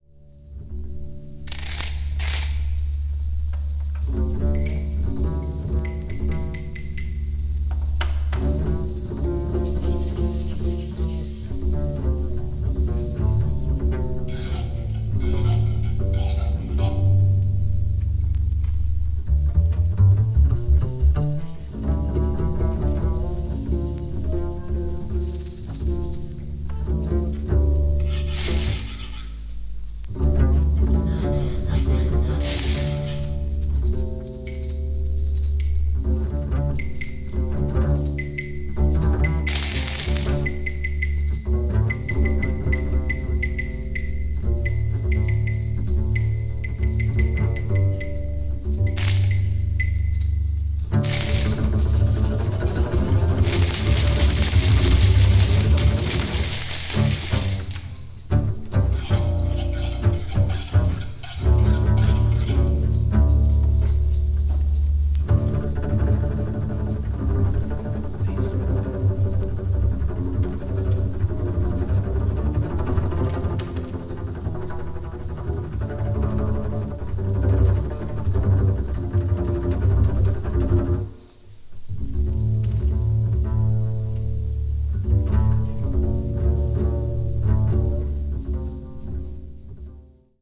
media : EX-/EX-(薄いスリキズによるわずかなチリノイズが入る箇所あり)
avant-jazz   free improvisation   free jazz   spiritual jazz